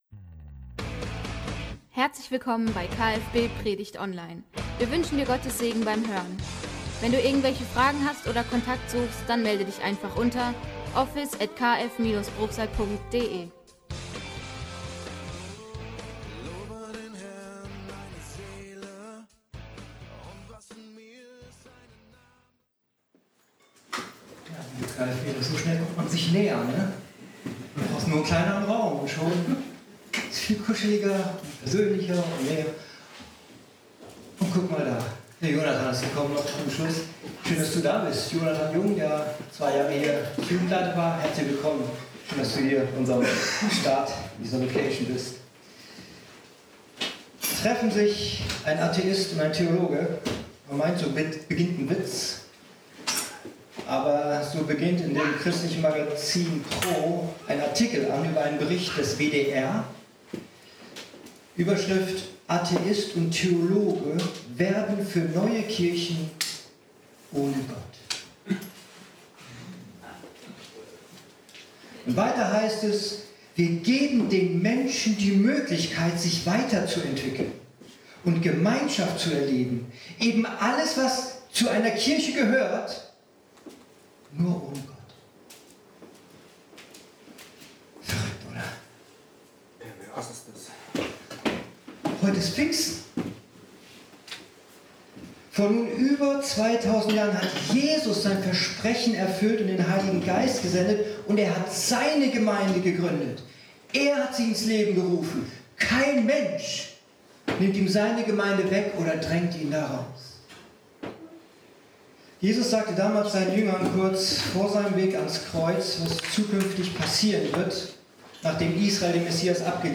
Wir müssen noch einige technische Dinge an die neue Location anpassen, bis alles wieder „rund“ läuft. Wir bitten daher noch um etwas Geduld, bis die Tonqualität der Predigt wieder auf gewohntem Niveau liegt…